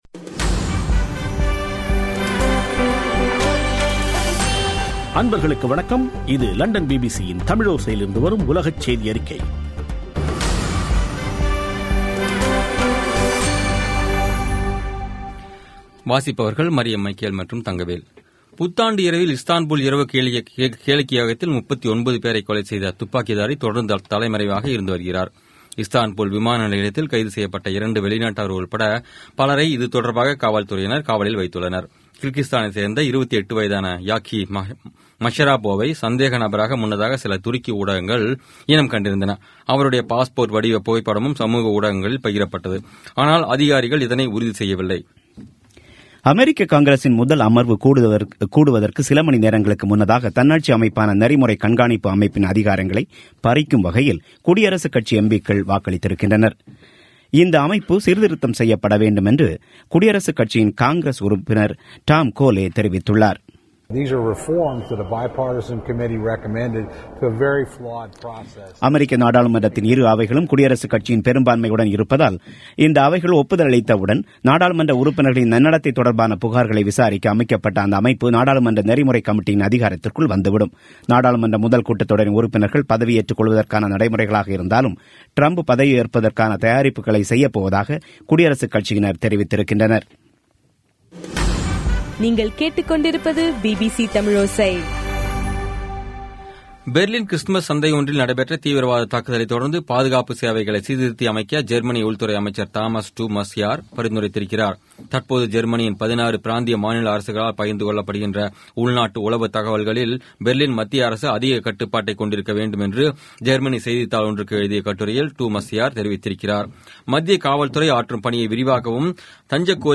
பிபிசி தமிழோசை செய்தியறிக்கை (03/11/2016)